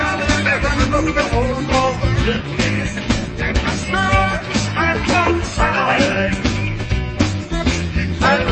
blues_blues.00005.mp3